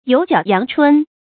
有腳陽春 注音： ㄧㄡˇ ㄐㄧㄠˇ ㄧㄤˊ ㄔㄨㄣ 讀音讀法： 意思解釋： 承春：指春天。舊時稱贊好官的話。